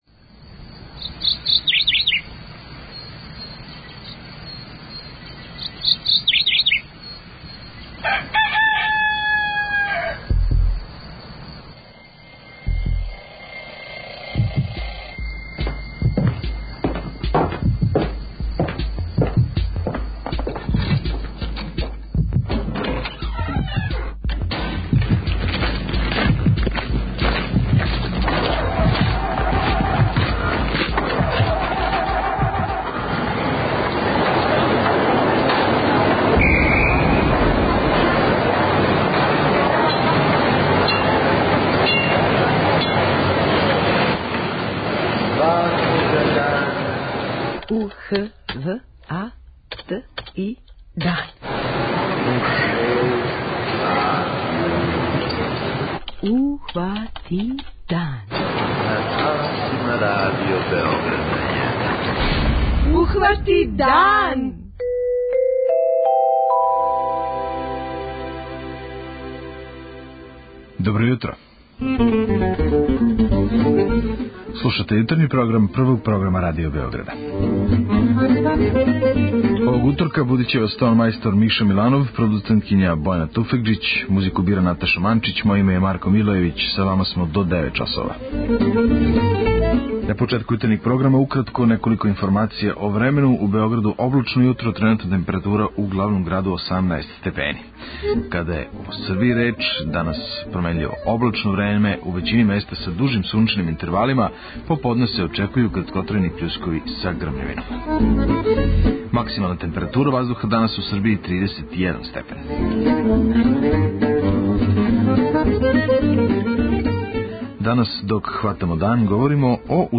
И овог јутра чућемо извештај наших репортера са Олимпијских игара у Бразилу.
преузми : 21.57 MB Ухвати дан Autor: Група аутора Јутарњи програм Радио Београда 1!